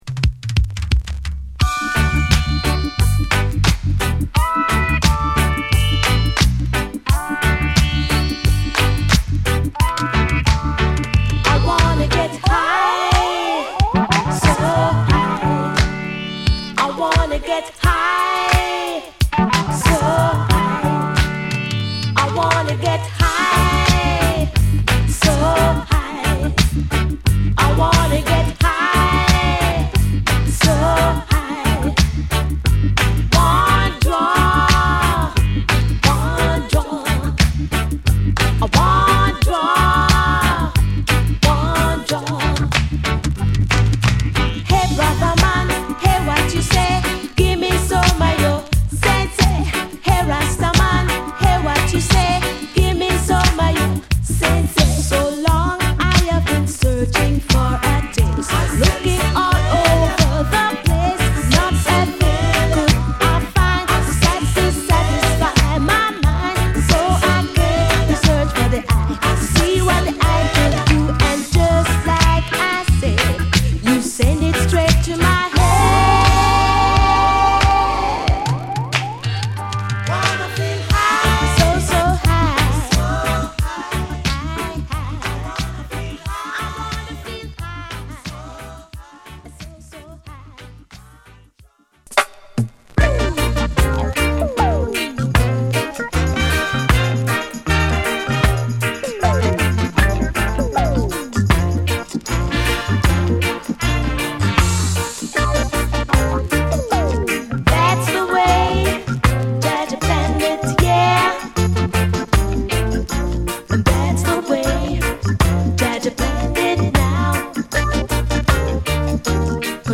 気持ちの良いリズムで爽快になります